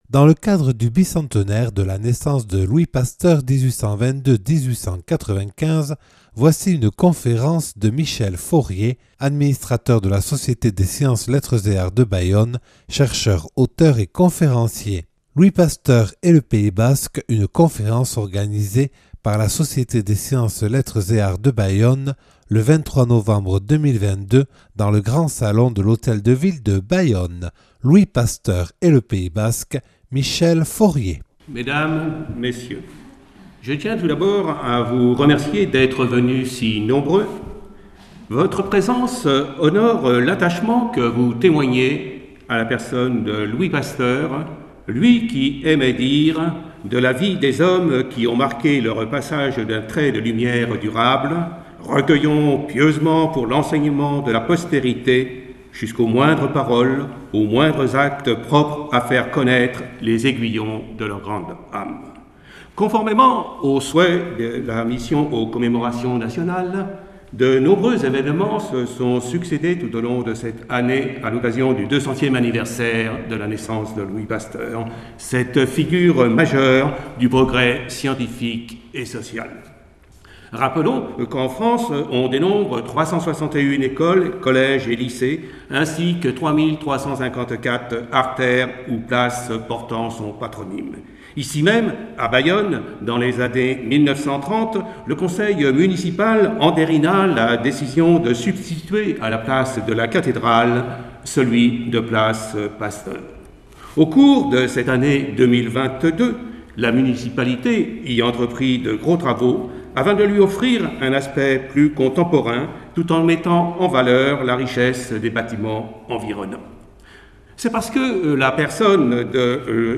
(Enregistrée le 23/11/2022 à la Mairie de Bayonne et organisé par la Société des Sciences Lettres et Arts de Bayonne).